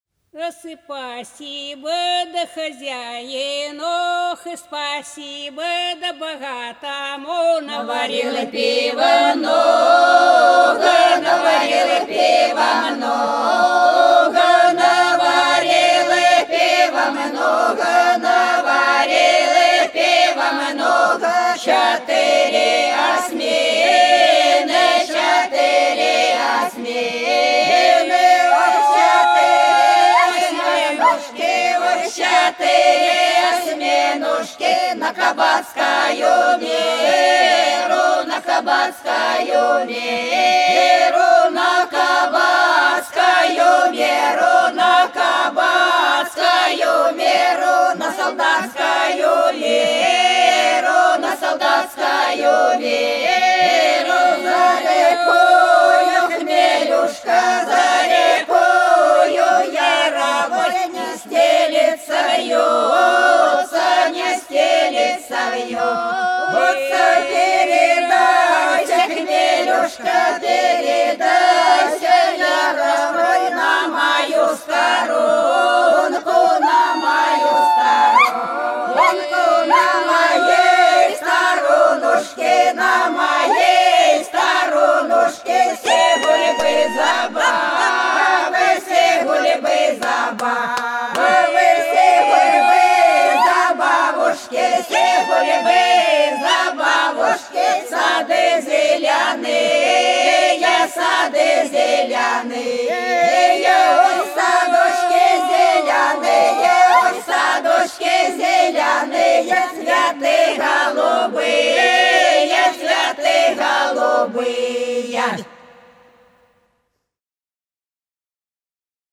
По-над садом, садом дорожка лежала Спасибо хозяину - величальная (с.Фощеватово, Белгородская область)
23_Спасибо_хозяину_(величальная).mp3